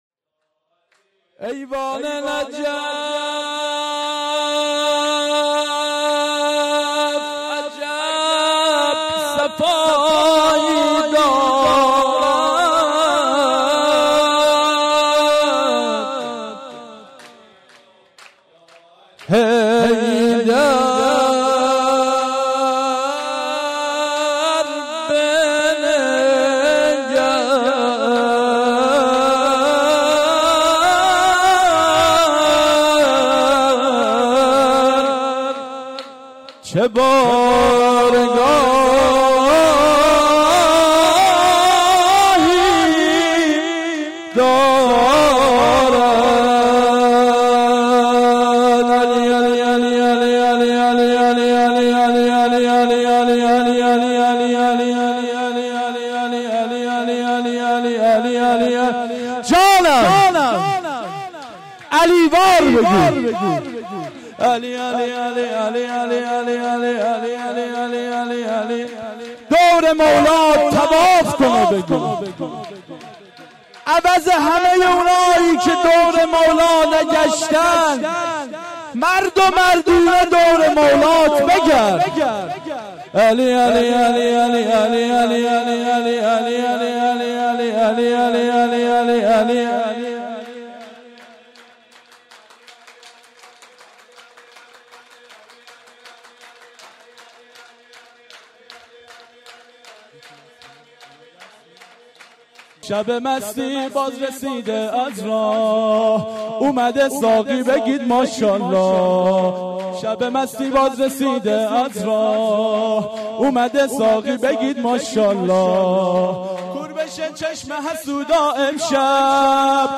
سرود «1»